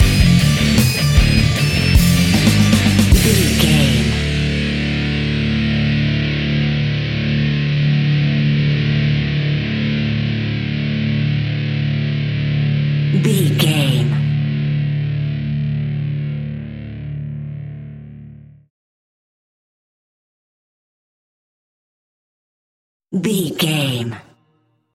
Epic / Action
Fast paced
Aeolian/Minor
heavy metal
distortion
Rock Bass
heavy drums
distorted guitars
hammond organ